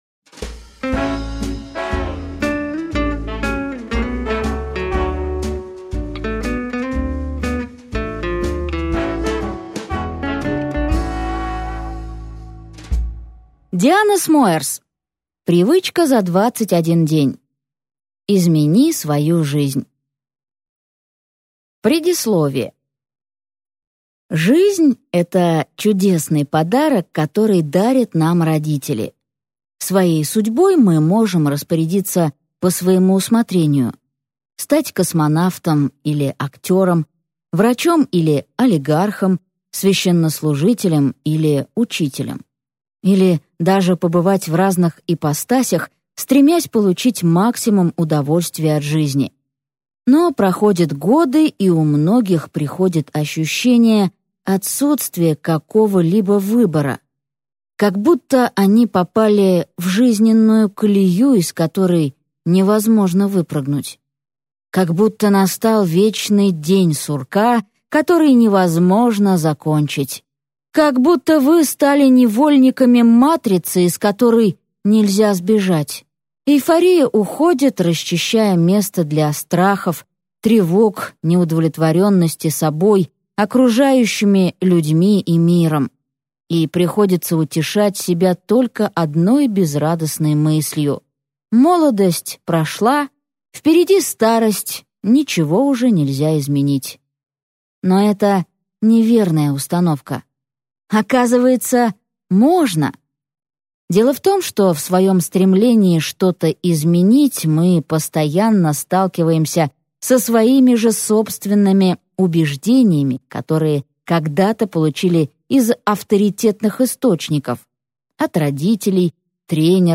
Аудиокнига Привычка за 21 день: как изменить свою жизнь | Библиотека аудиокниг
Прослушать и бесплатно скачать фрагмент аудиокниги